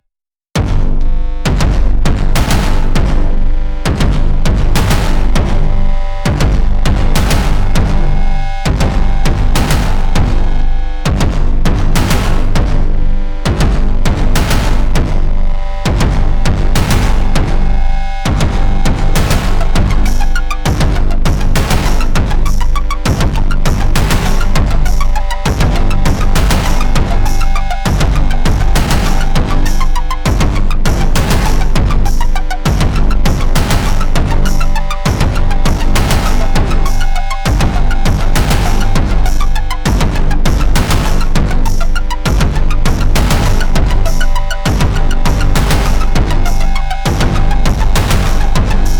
swarm le thicc